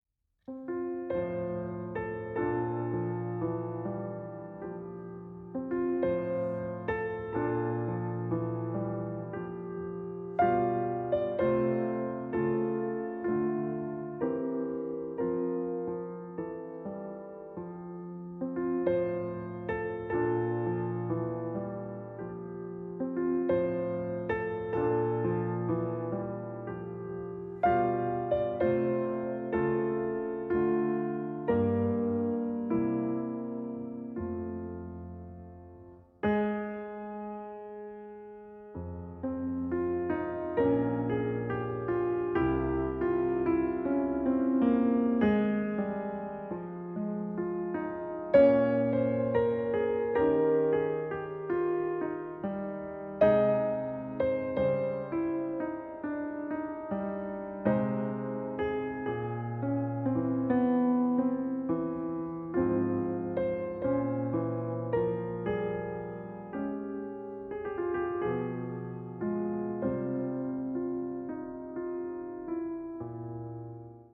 piano
Vif, très marqué